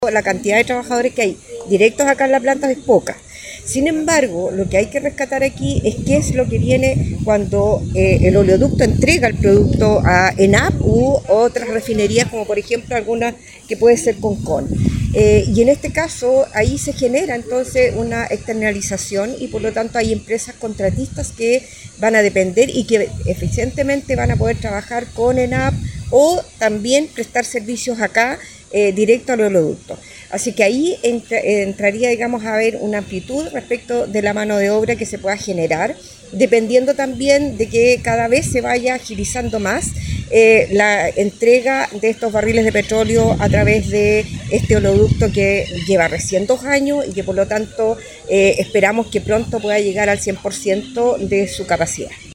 La autoridad profundizó en lo que significa para la región transportar de forma directa el petróleo desde Argentina.